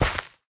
Minecraft Version Minecraft Version snapshot Latest Release | Latest Snapshot snapshot / assets / minecraft / sounds / block / rooted_dirt / step6.ogg Compare With Compare With Latest Release | Latest Snapshot
step6.ogg